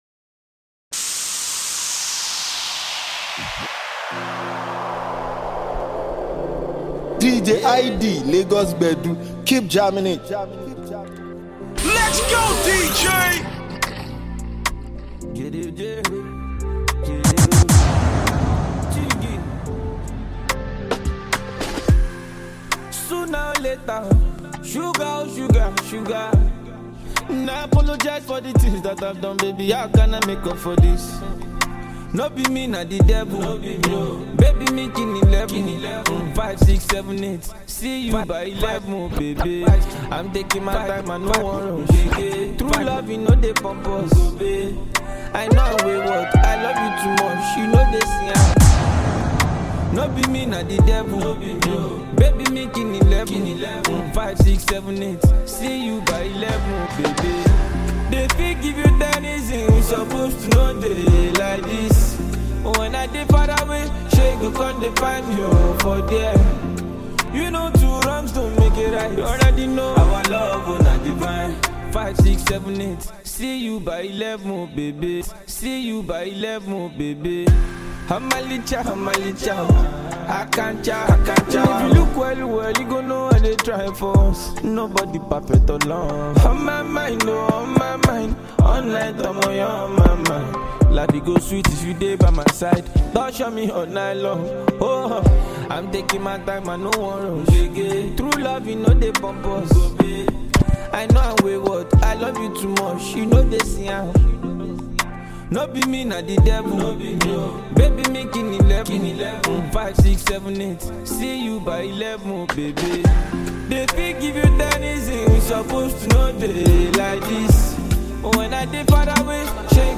a highly talented Nigerian disc jockey.